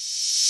Pony Open Hat.wav